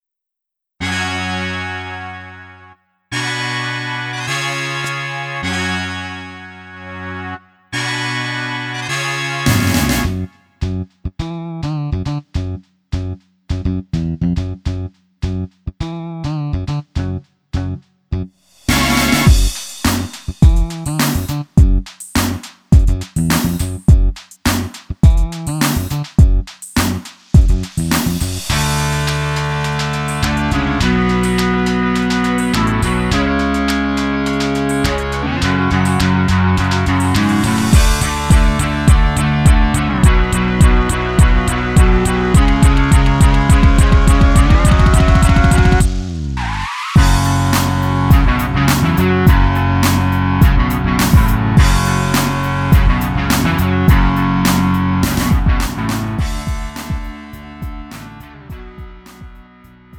고음질 반주
음정 원키 장르 가요